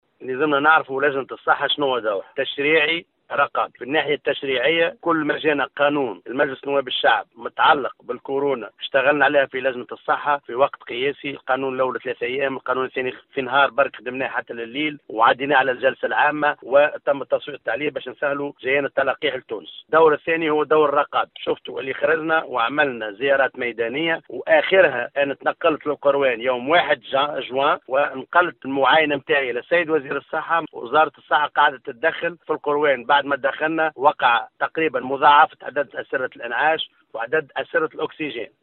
Dans une déclaration accordée aujourd’hui à  Tunisie numérique le président de la commission parlementaire de la santé Ayachi Zammmel a déclaré qu’à chaque fois qu’il a été question d’une nouvelle législation relative au coronavirus, la commission a travaillé dur pour que la loi soit prête le plus tôt possible.